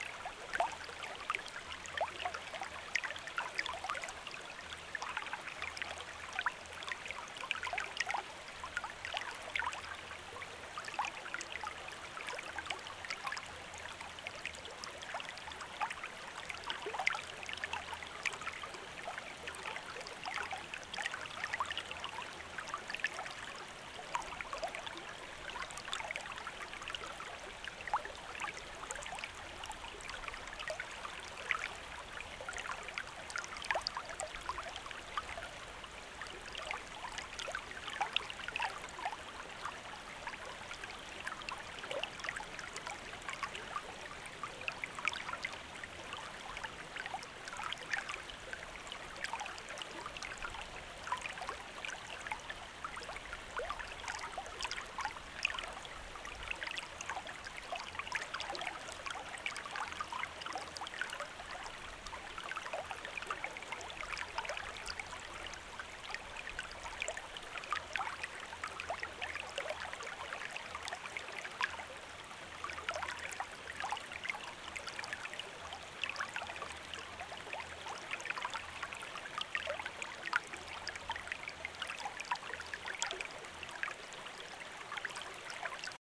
دانلود صدای طبیعت و پرندگان